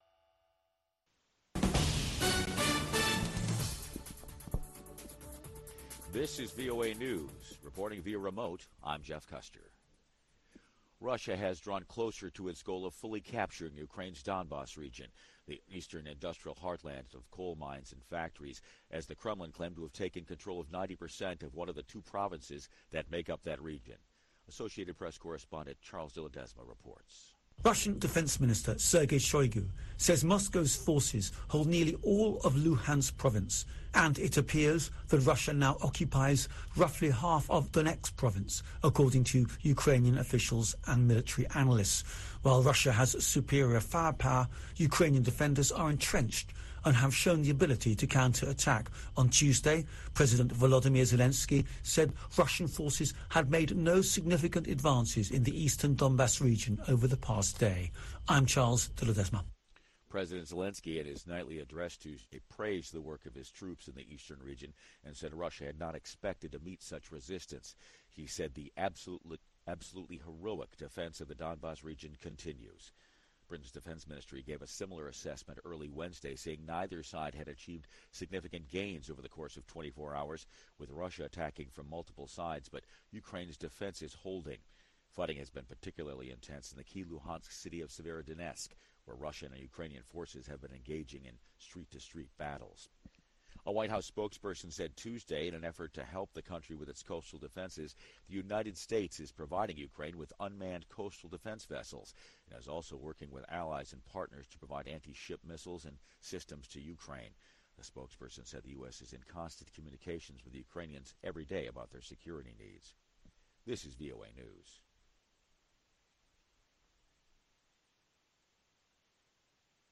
Voice of America: VOA Newscasts